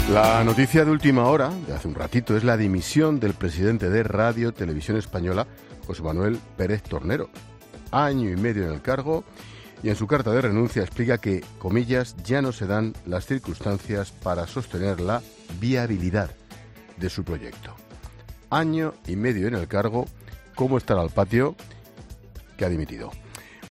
Te da más detalles el director de 'La Linterna', Ángel Expósito